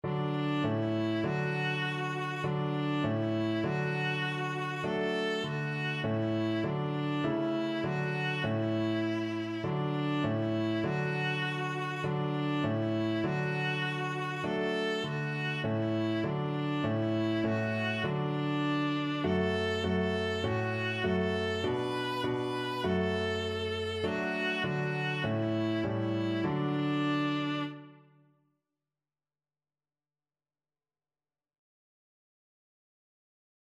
Viola
D major (Sounding Pitch) (View more D major Music for Viola )
Simply
4/4 (View more 4/4 Music)
Traditional (View more Traditional Viola Music)